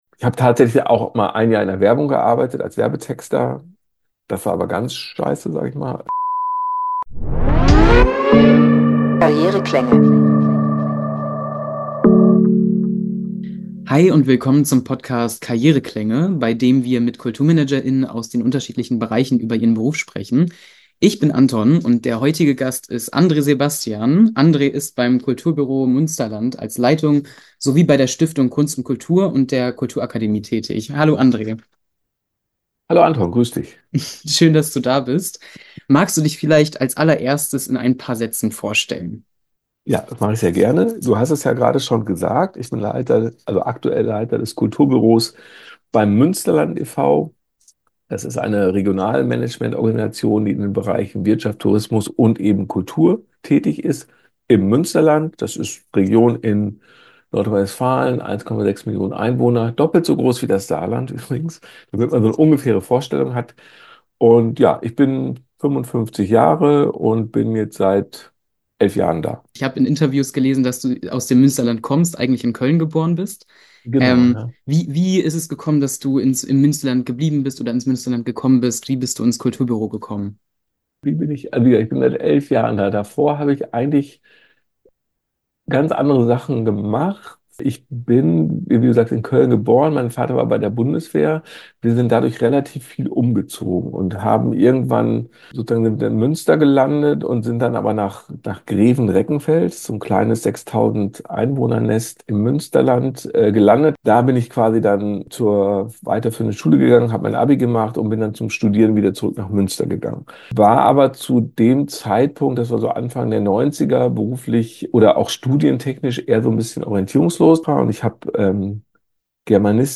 Karriereklänge – Talk